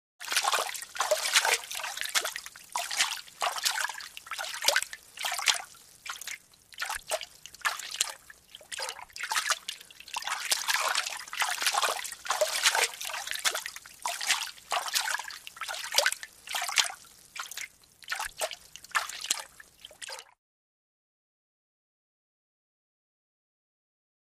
Wade Through Water 2; Hands Splash In And Out Of Water, Lightly.